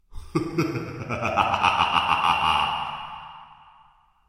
Звуки злодея
Саркастический смех звучит